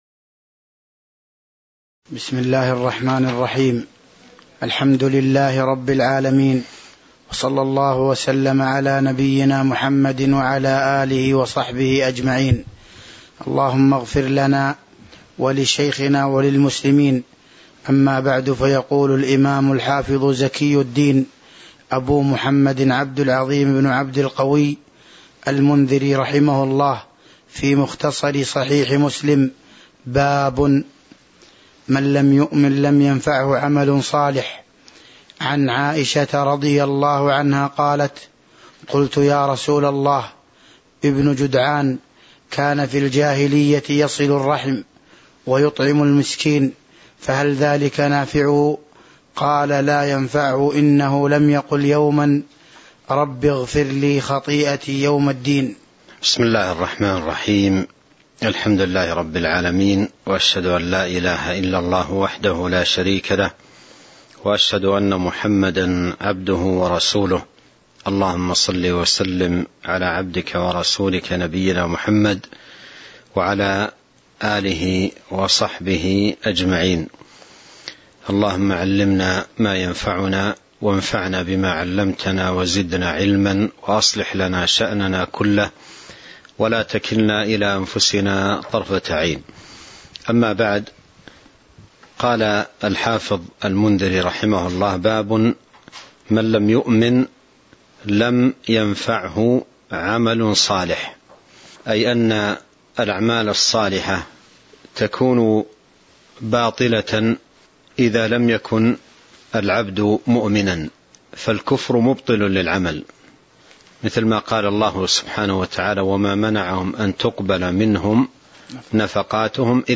تاريخ النشر ٢٩ صفر ١٤٤٢ هـ المكان: المسجد النبوي الشيخ